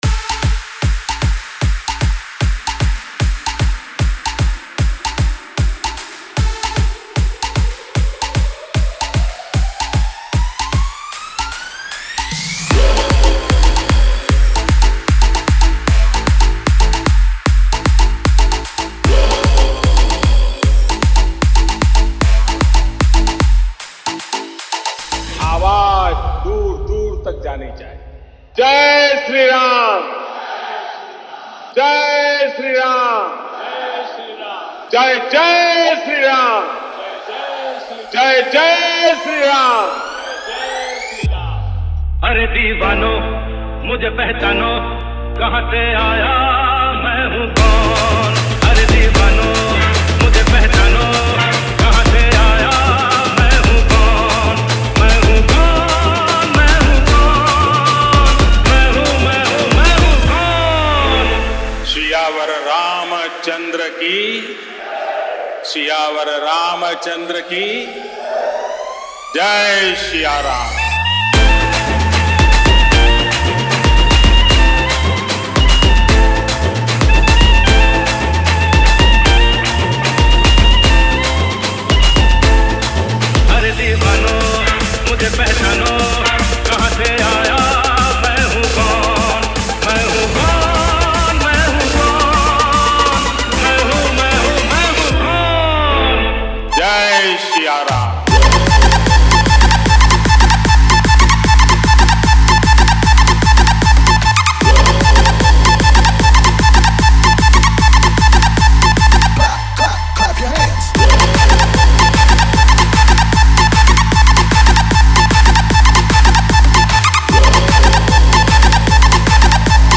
Ram Navami Special Dj Songs Download